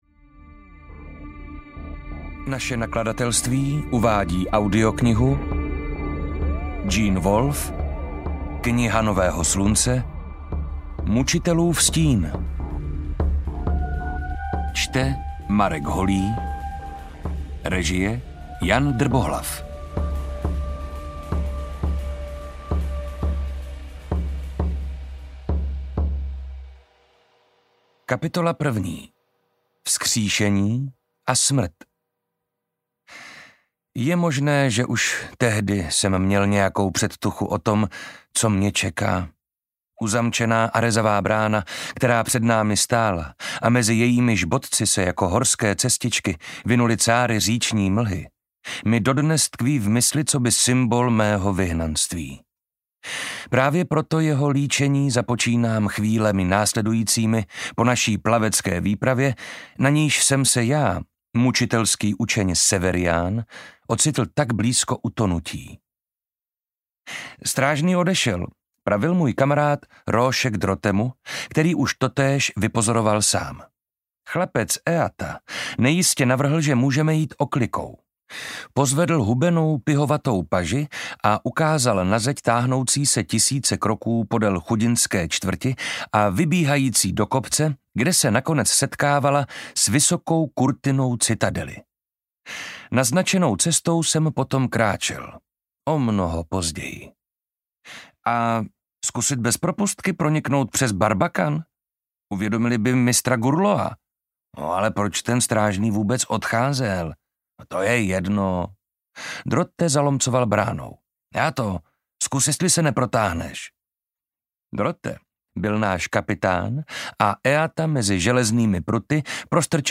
Mučitelův stín audiokniha
Ukázka z knihy
• InterpretMarek Holý